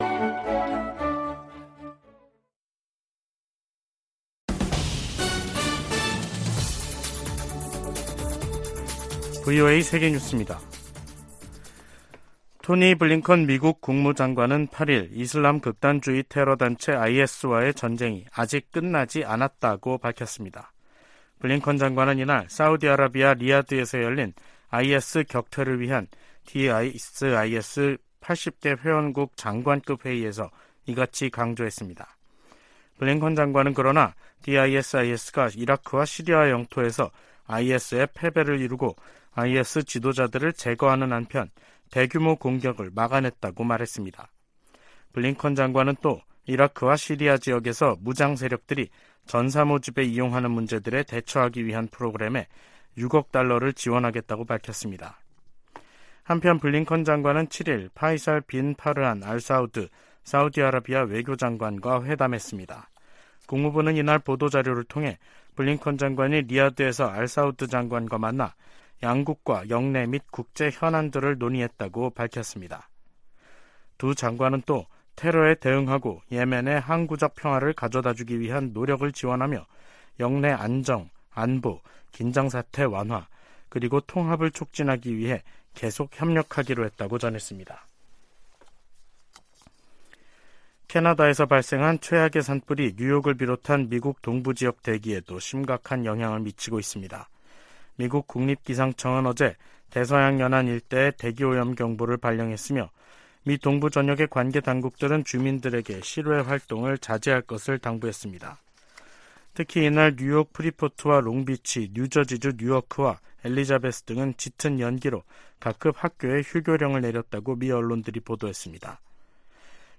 VOA 한국어 간판 뉴스 프로그램 '뉴스 투데이', 2023년 6월 7일 8부 방송입니다. 미국은 국제원자력기구 이사회에서 북한의 전례 없는 미사일 발사를 거론하며 도발적 행동에 결과가 따를 것이라고 경고했습니다. 한국의 윤석열 정부는 개정된 안보전략에서 '종전선언'을 빼고 '북 핵 최우선 위협'을 명시했습니다. 백악관의 커트 캠벨 인도태평양조정관은 북한 문제를 중국과의 주요 협력 대상 중 하나로 꼽았습니다.